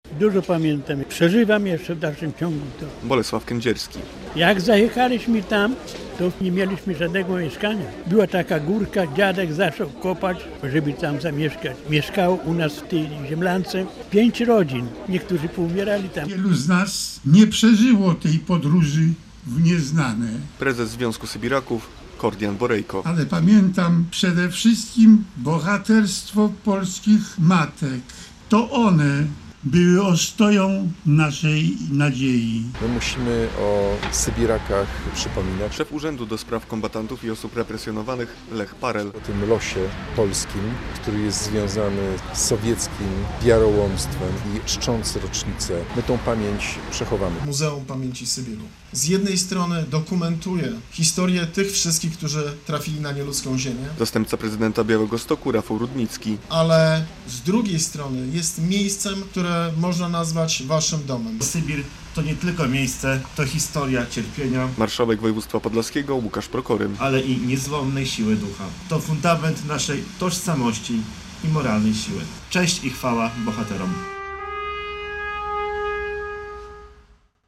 Obchody 85. rocznicy deportacji na Sybir - relacja